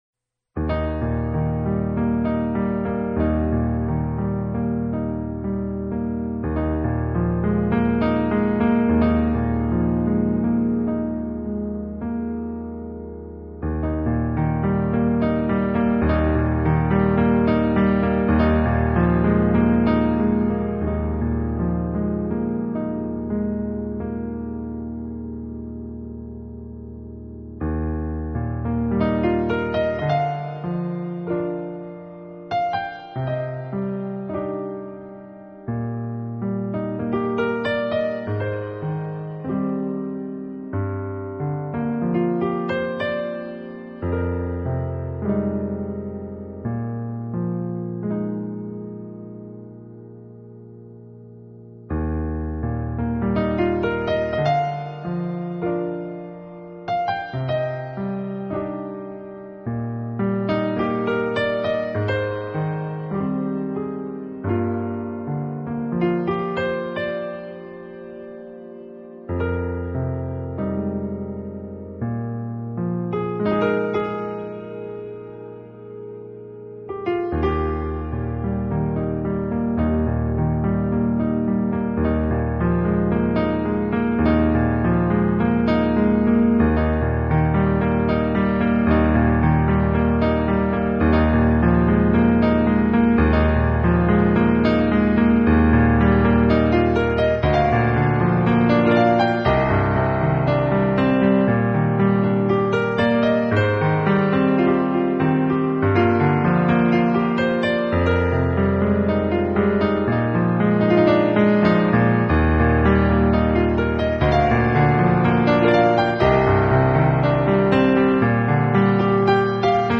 【爵士专辑】
音阶中揉入打击乐和电子音乐，再加上吉它、电贝斯、长笛和萨克斯管演奏片断。